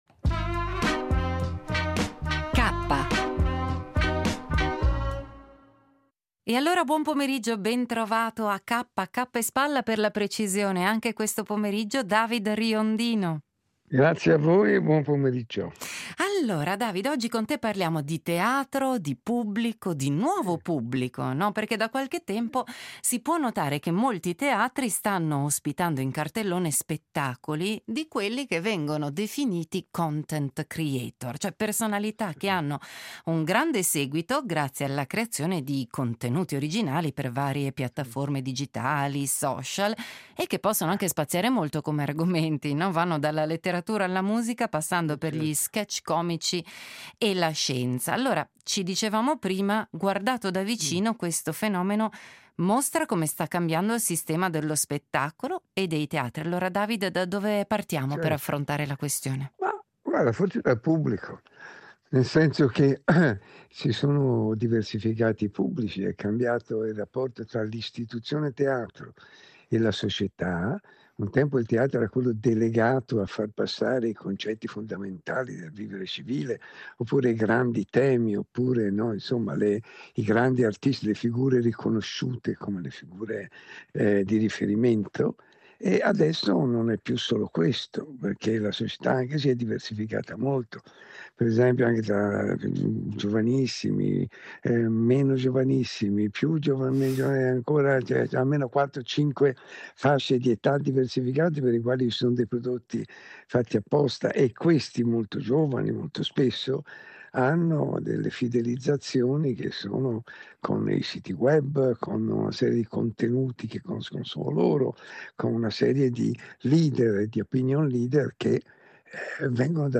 L’editoriale del giorno firmato da David Riondino